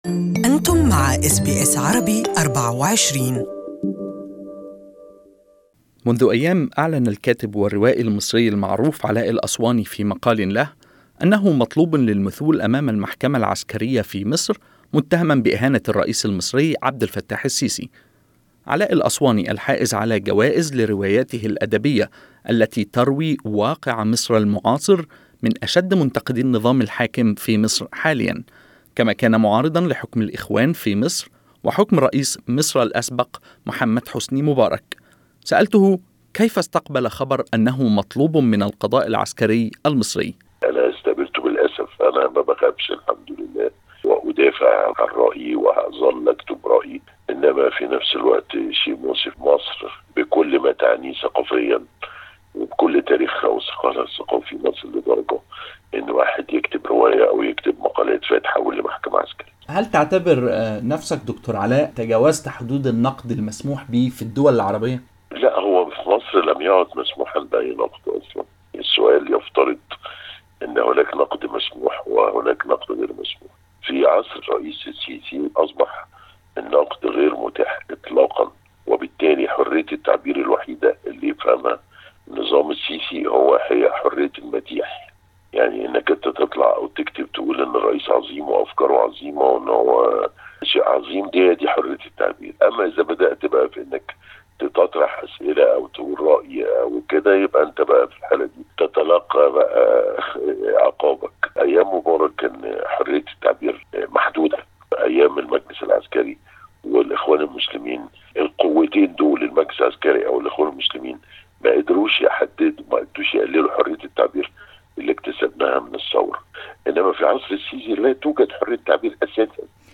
In an exclusive interview with SBS Arabic24, he said he believed that freedom of speech was being hit hard by Egypt’s strong man leader Abel Fattah El Sisi.